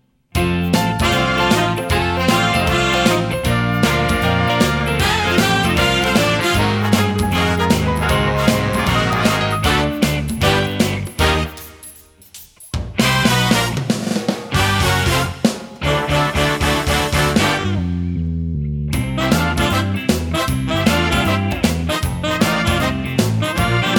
One Semitone Down Jazz / Swing 3:09 Buy £1.50